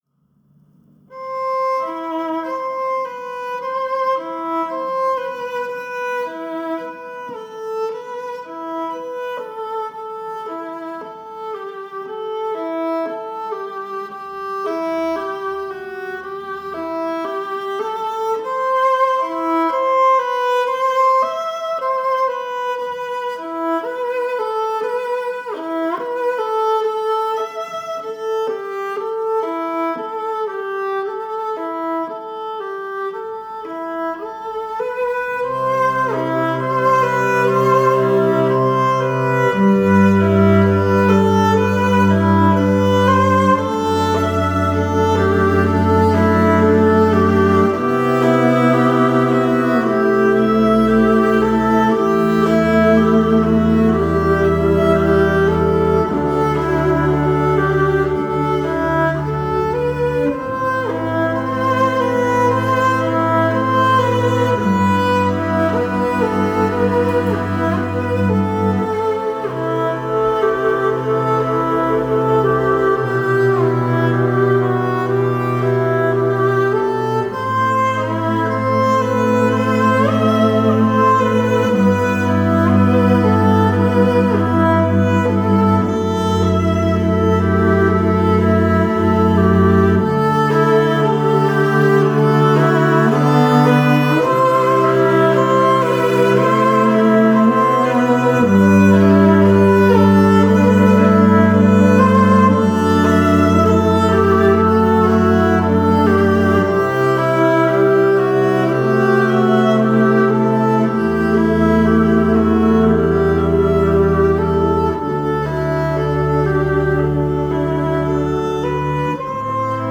نیازی به کلام نیست تا قلب با موسیقی همسو شود.